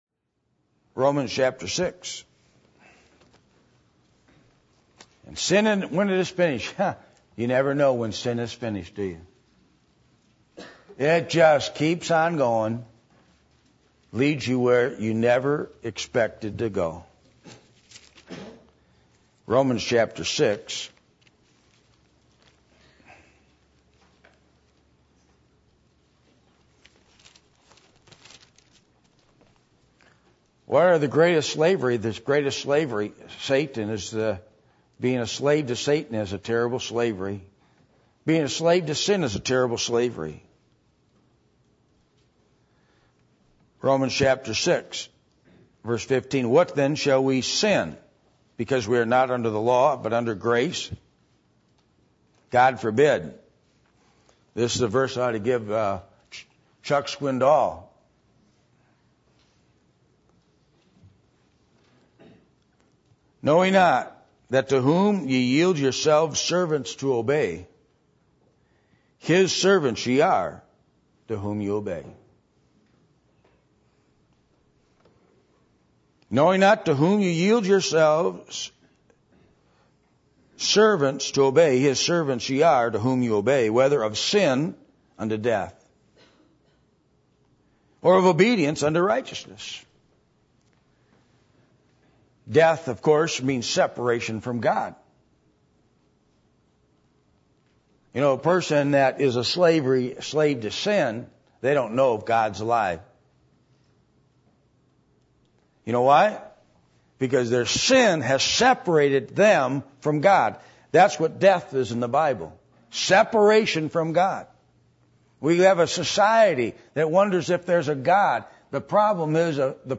1 Corinthians 7:21-24 Service Type: Sunday Morning %todo_render% « How Big Is Your God?